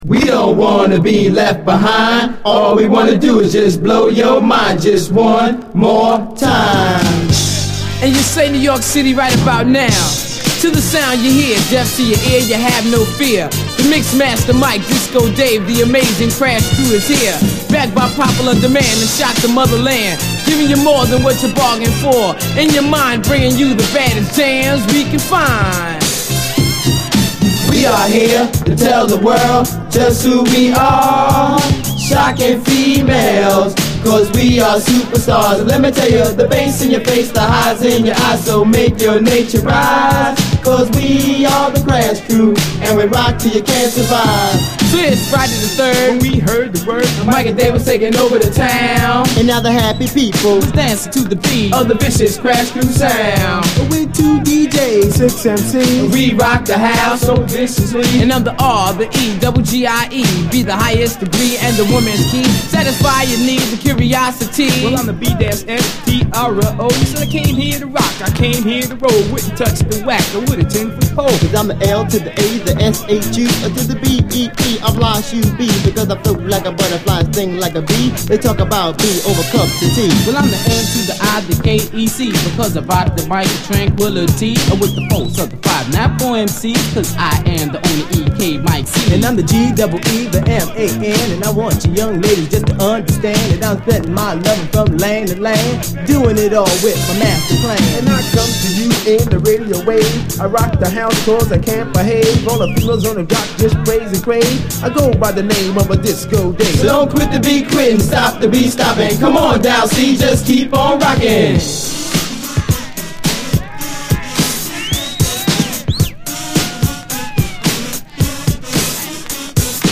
DISCO, HIPHOP
レア・オールドスクール・ディスコ・ラップ！